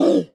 MONSTER_Deep_Hurt_Subtle_mono.wav